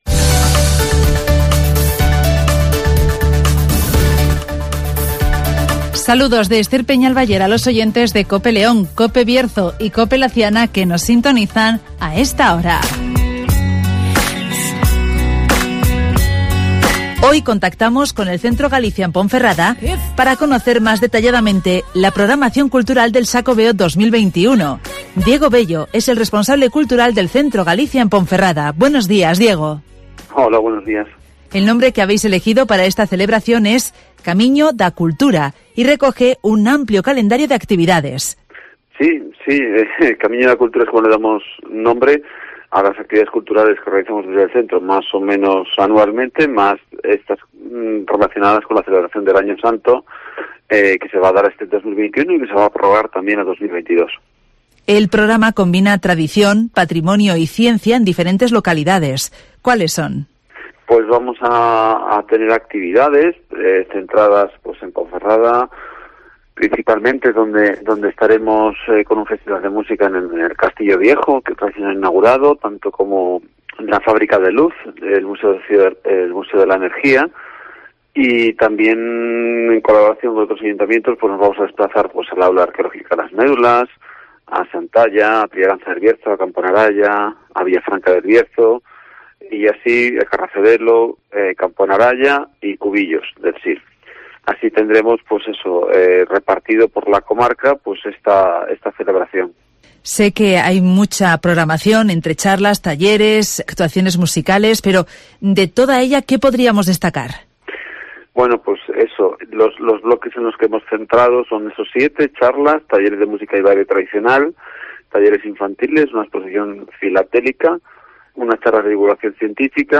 El Centro Galicia en Ponferrada ha presentado la programación cultural del Xacobeo 2021 (Entrevista